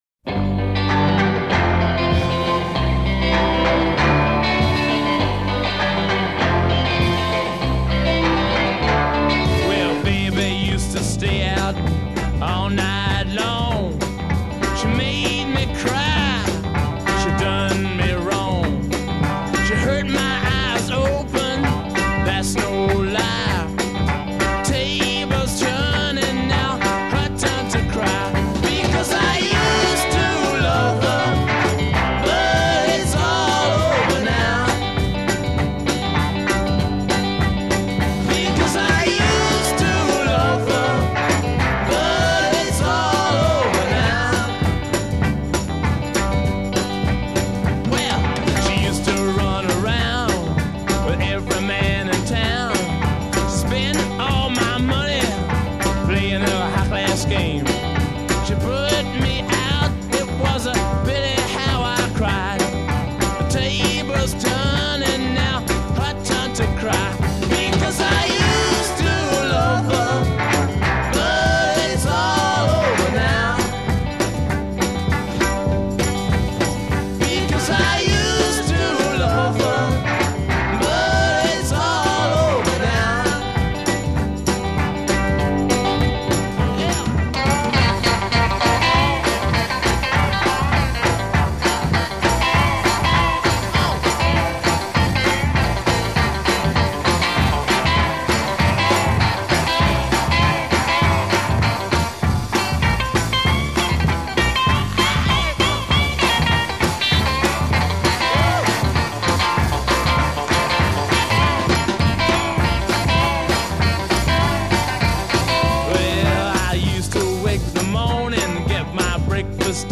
intro 0:00 8 guitar chords (w/ echo)
refrain : 8 chorus & instrumental fill b
B solo : 12 12-bar blues format guitar solo